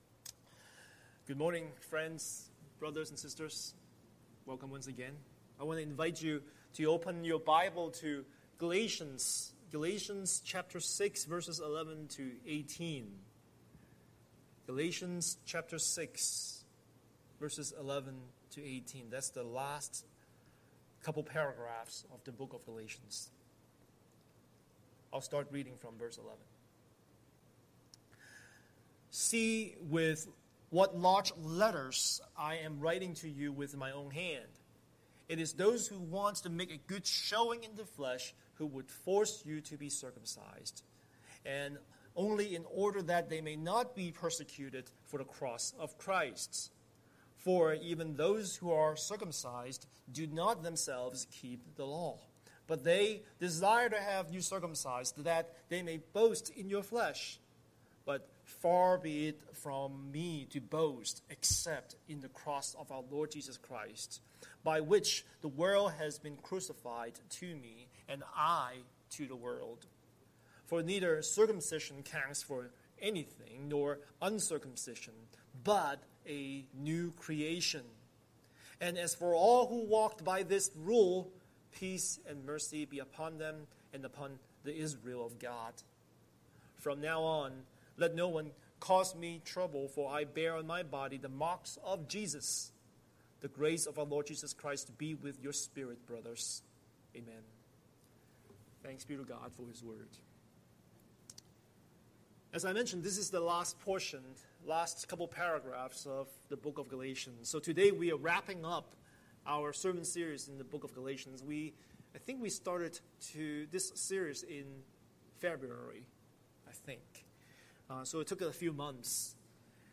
Scripture: Galatians 6:11-18 Series: Sunday Sermon